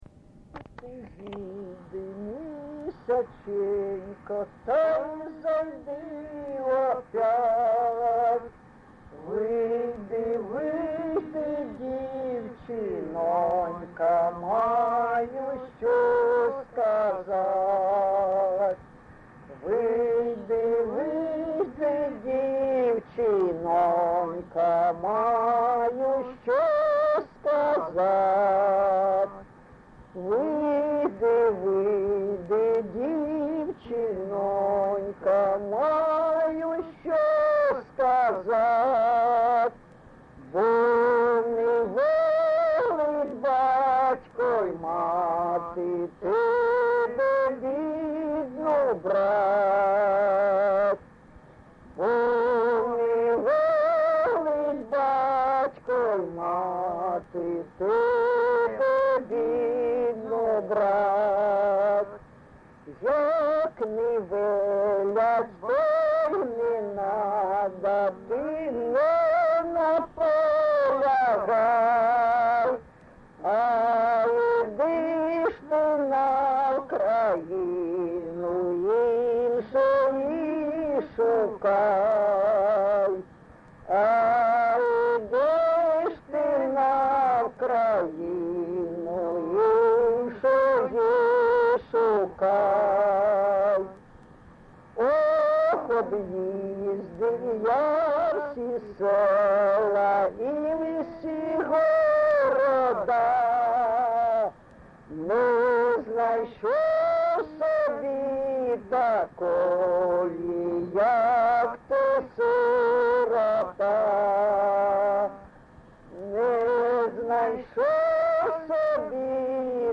ЖанрПісні з особистого та родинного життя
Місце записум. Дебальцеве, Горлівський район, Донецька обл., Україна, Слобожанщина